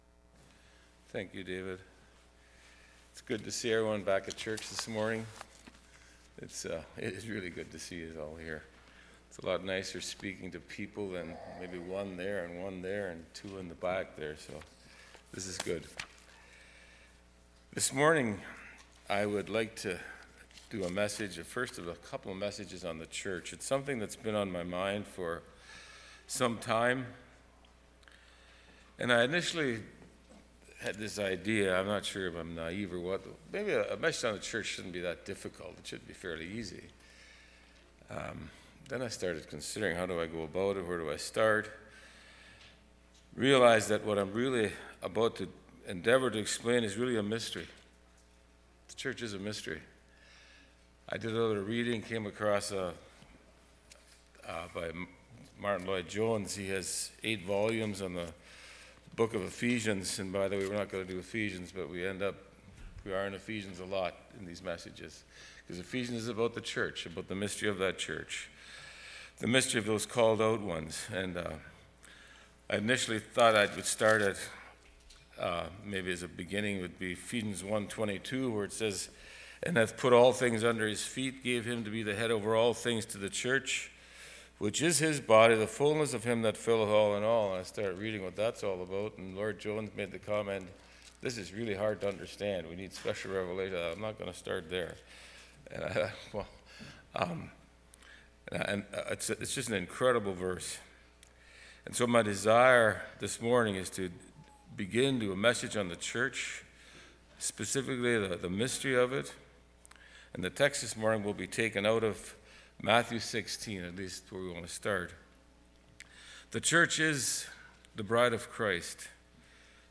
(*First portion of sermon recording missed)